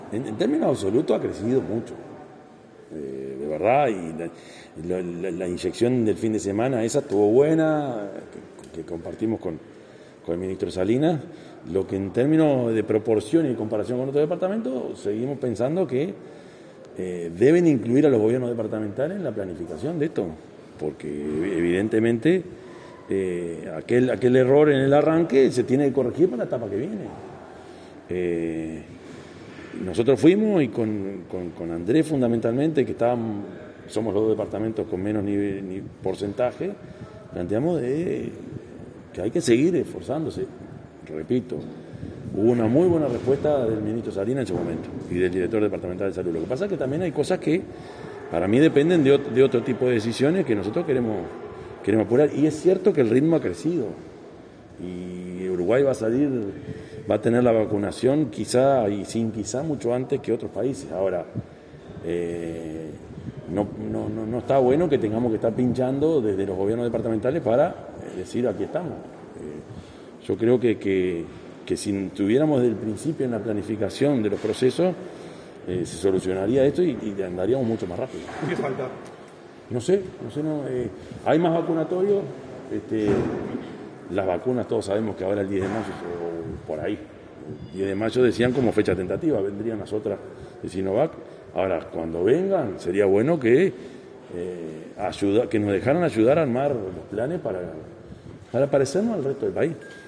Yamandú Orsi, intendente de Canelones, expresó en rueda de prensa este viernes que «deben incluir a los gobiernos departamentales en la planificación» de la vacunación contra el coronavirus.